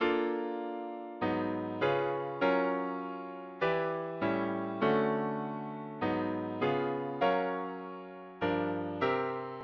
MIDI sequence